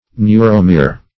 Search Result for " neuromere" : The Collaborative International Dictionary of English v.0.48: Neuromere \Neu"ro*mere\, n. [Neuro- + -mere.]